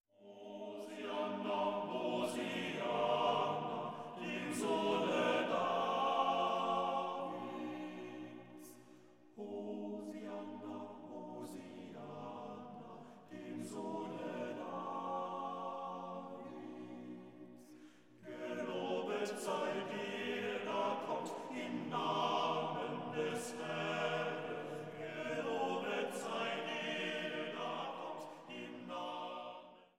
Weihnachtliche Chorsätze und Orgelmusik